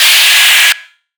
DDW4 SFX TV NOISE.wav